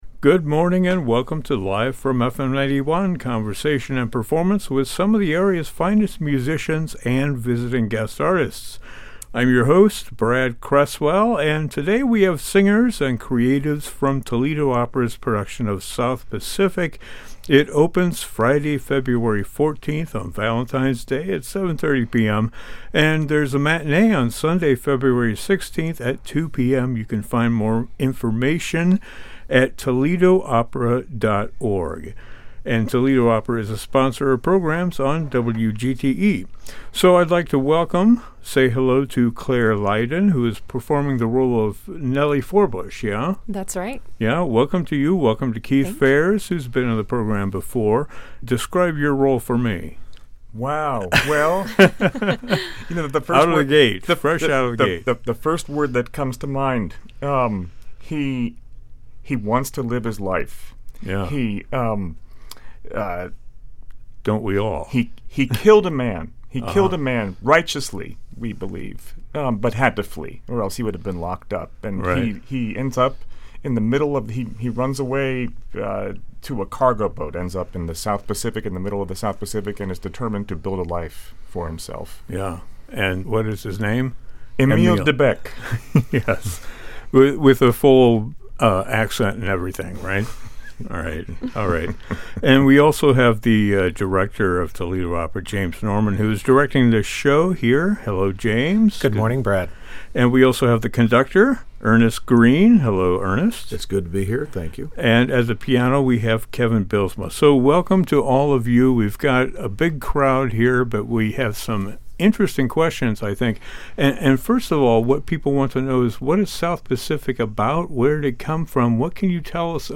(Please note that the musical selections have been truncated due to copyright concerns)